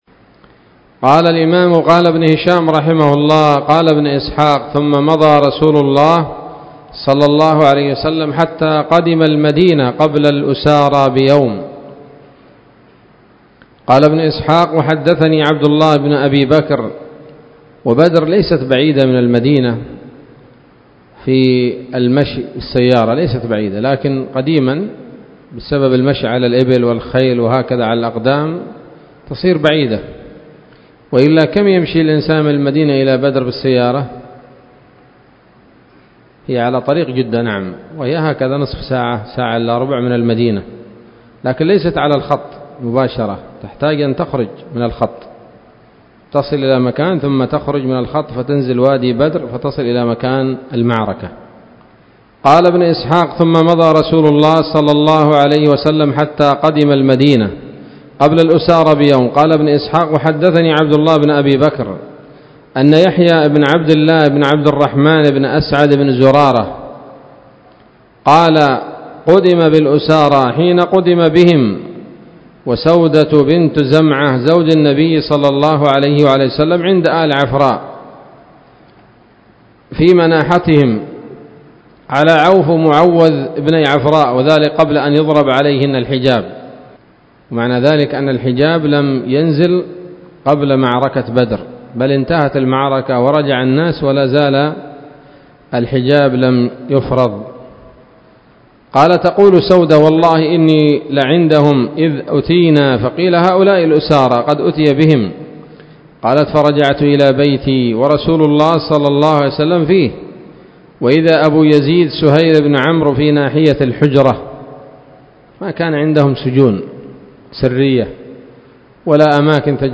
الدرس الثالث والعشرون بعد المائة من التعليق على كتاب السيرة النبوية لابن هشام